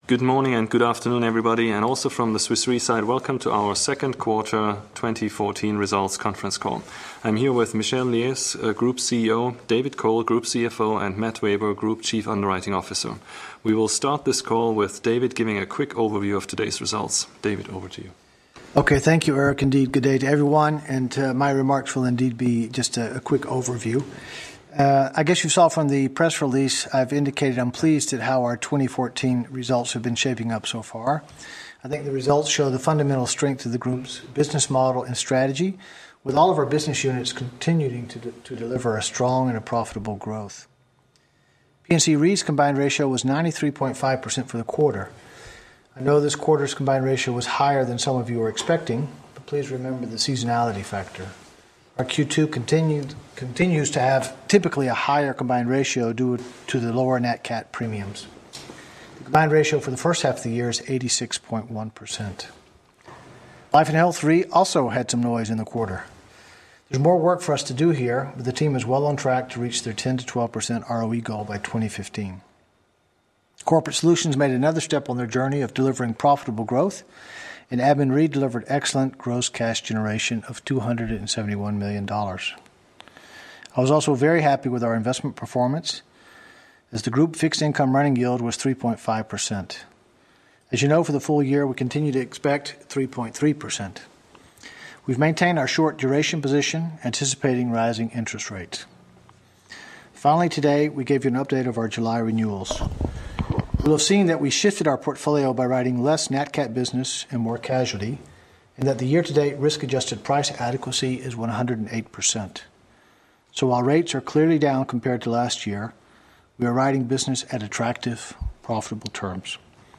Analysts Conference call recording
Q2_2014_Analysts_Call.mp3